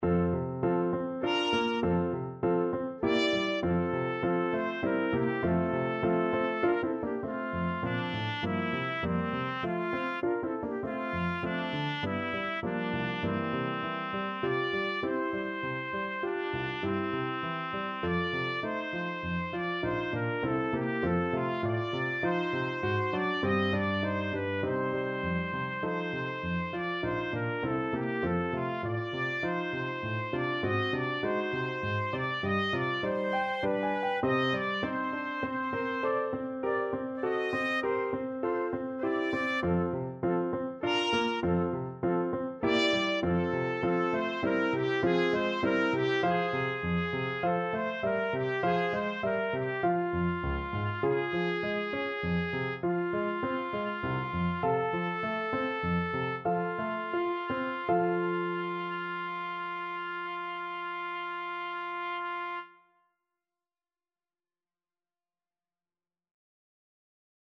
Trumpet
F major (Sounding Pitch) G major (Trumpet in Bb) (View more F major Music for Trumpet )
3/4 (View more 3/4 Music)
~ = 100 Allegretto grazioso (quasi Andantino) (View more music marked Andantino)
C5-Eb6
Classical (View more Classical Trumpet Music)